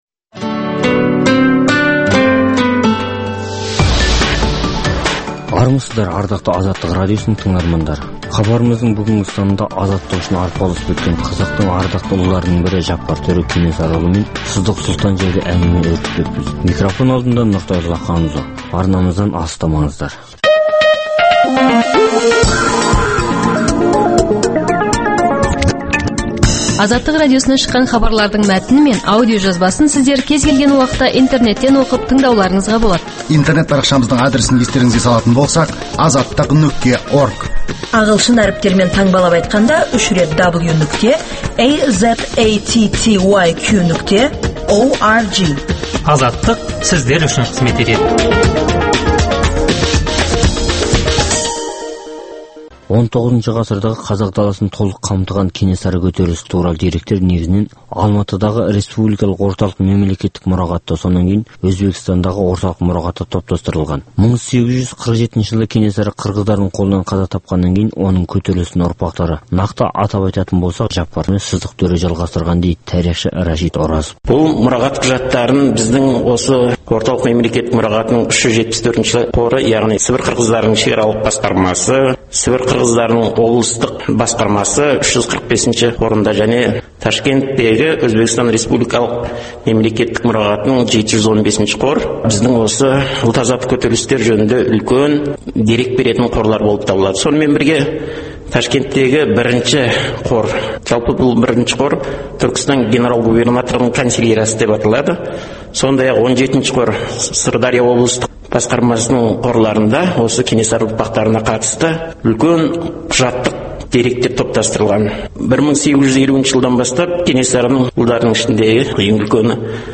Өткен тарихты зерделеу, ақтаңдақтар мен ұлт тарихындағы қиын-қыстау күндердің бүгінгі тарихта бағалануы тұрғысында тарихшы – зерттеушілермен өткізілетін сұхбат, талдау хабарлар.